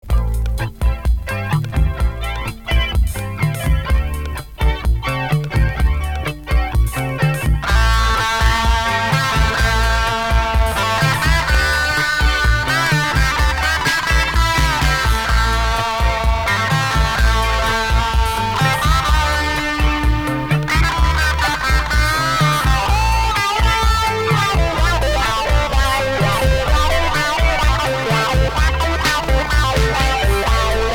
Pop psychédélique